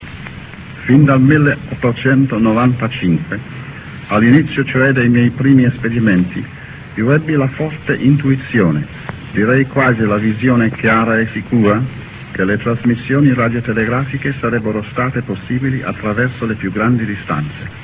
real audio G.Marconi voice 1